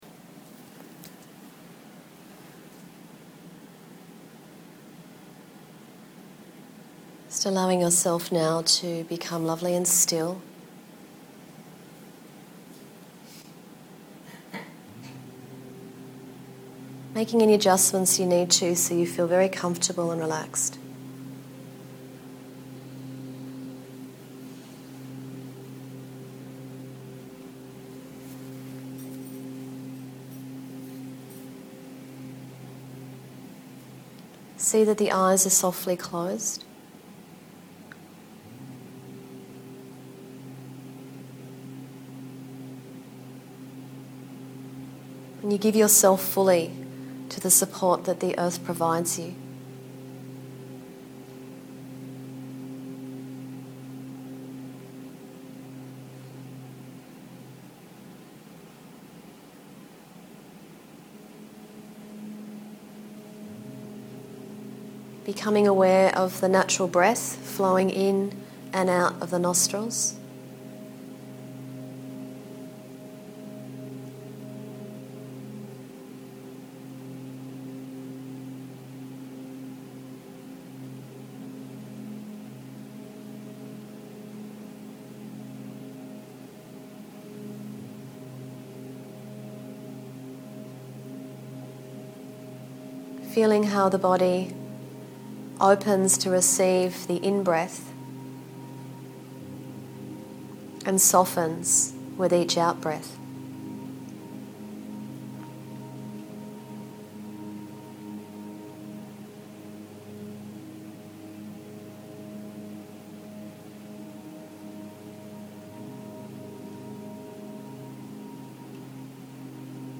Here is an Autumn meditation for you to enjoy.